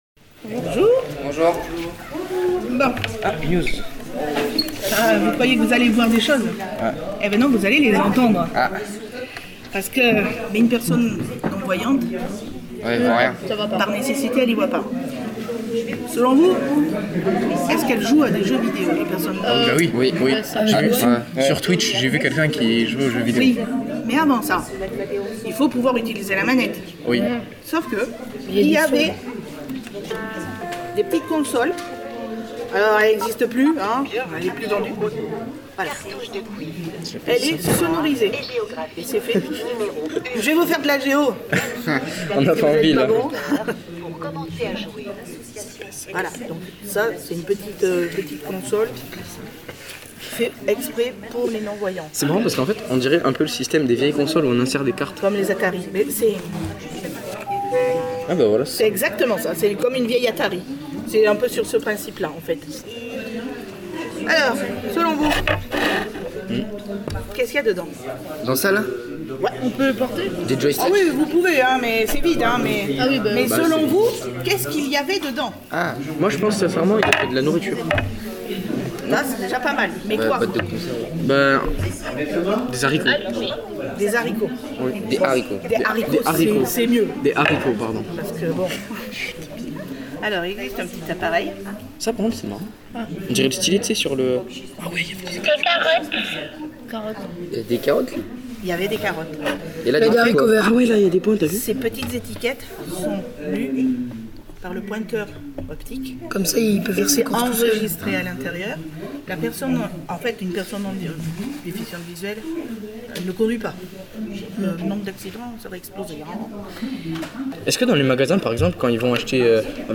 NOTRE ACTU SURPRISE A L’ASSEMBLEE GENERALE 2026 NOS ACTIONS 2025 ET PLUS SI AFFINITES APPRENDRE L’OUTIL INFORMATIQUE ! SURPRISE A L’ASSEMBLEE GENERALE 2026 Nous avons renoué avec le concept d’inviter des témoins locaux, avant de voter les décisions annuelles qui orientent la structure.